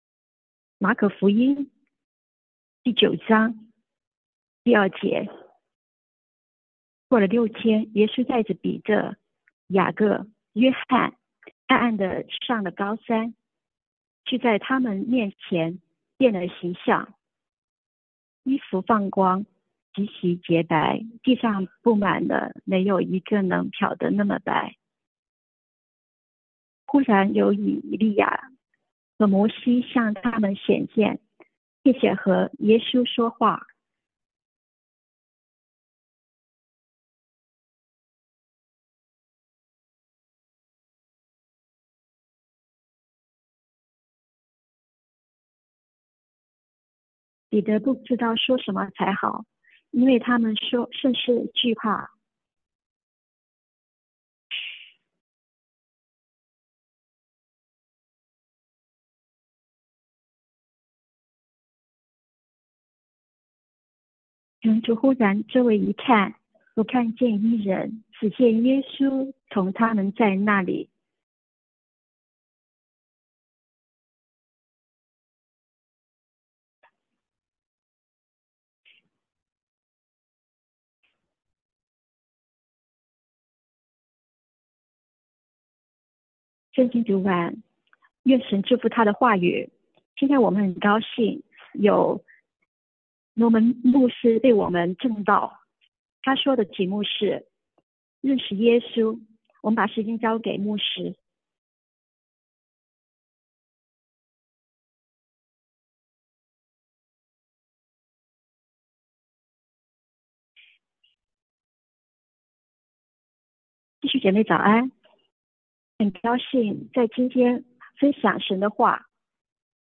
認識耶穌 – 普通話傳譯